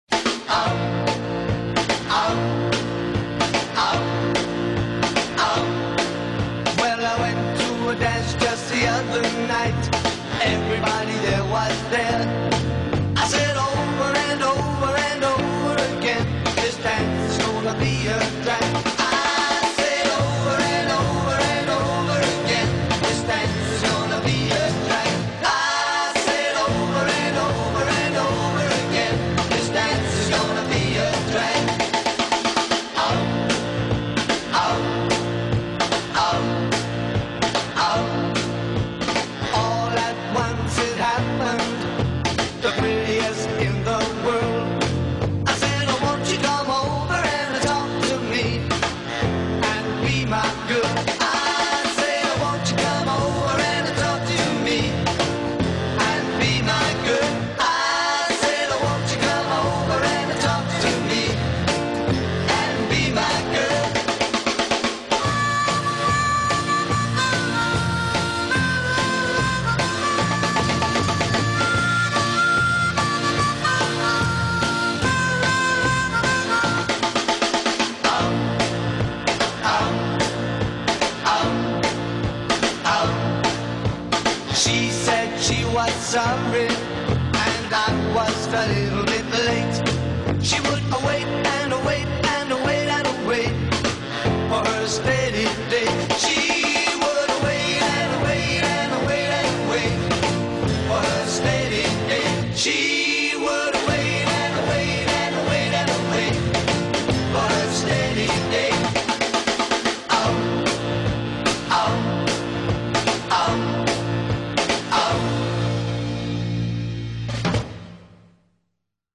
Жанры поп
бит
поп-рок